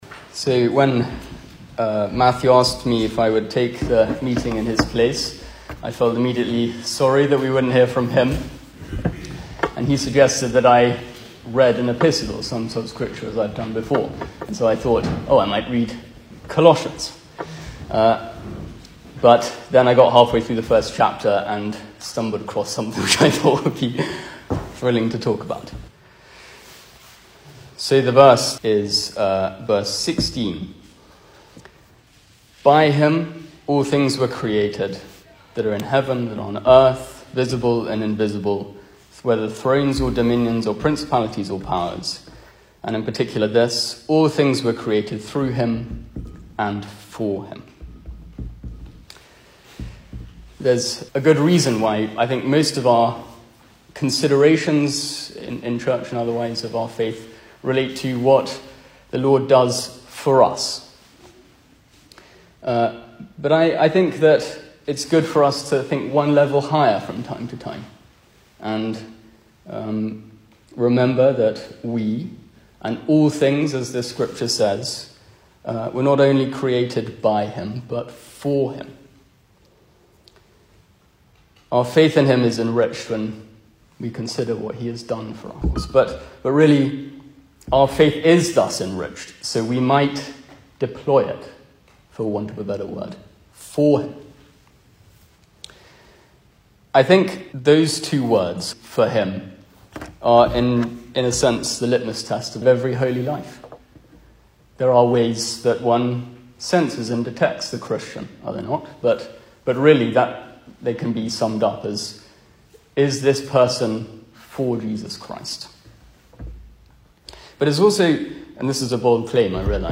Service Type: Weekday Evening
Single Sermons